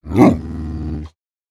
Minecraft Version Minecraft Version 25w18a Latest Release | Latest Snapshot 25w18a / assets / minecraft / sounds / mob / wolf / big / growl1.ogg Compare With Compare With Latest Release | Latest Snapshot
growl1.ogg